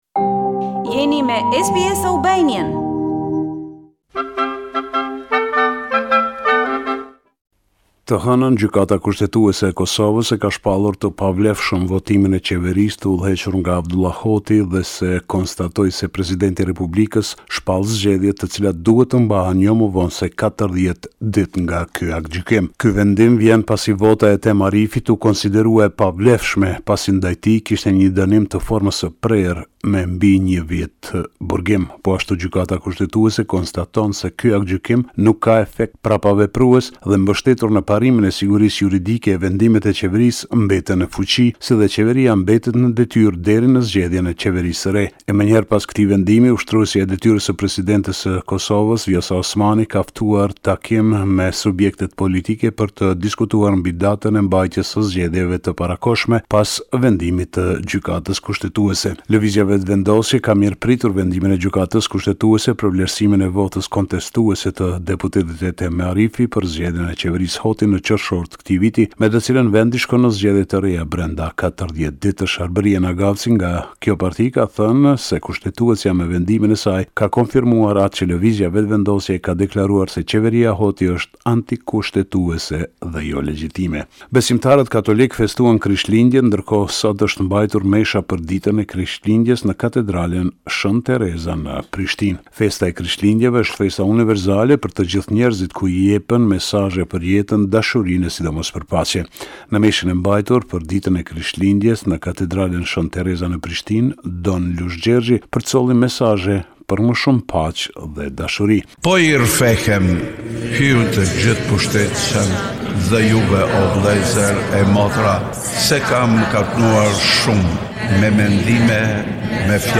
Raporti me te rejat me te fundit nga Kosova.